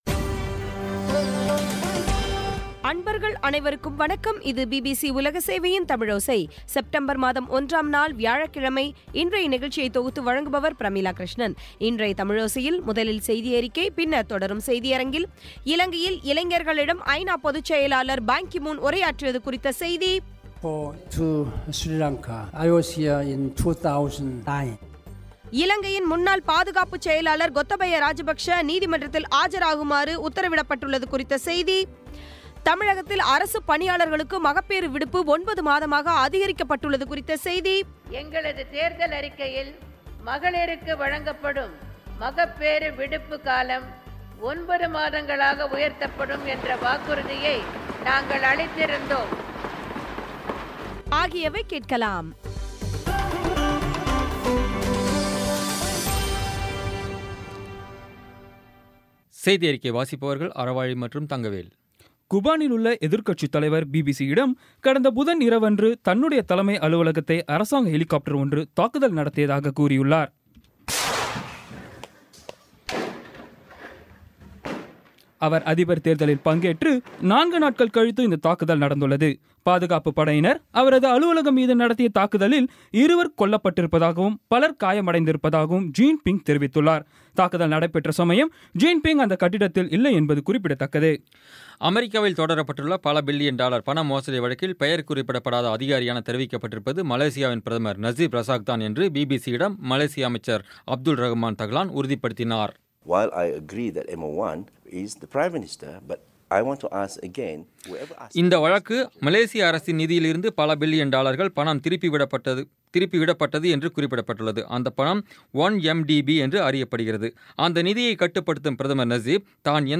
பி பி சி தமிழோசை செய்தியறிக்கை (01/09/2016)